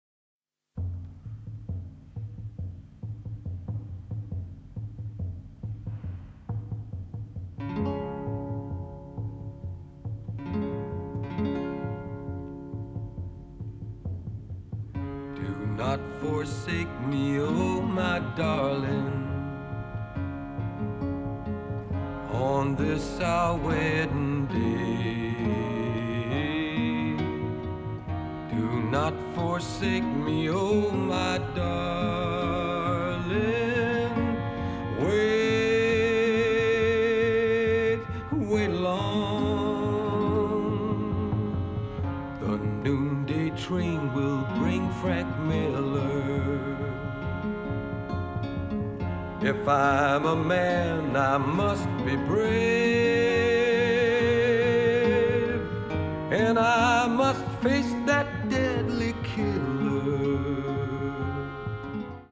baritone.